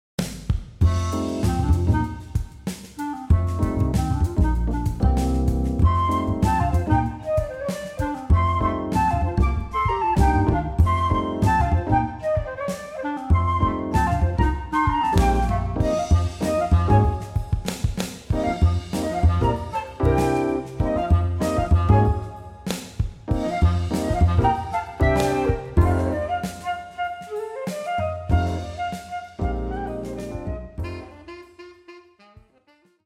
Top part (Reed 1) Flute, Alto Saxophone, Clarinet
Lower part (Reed 2) Flute, Tenor Saxophone, Clarinet.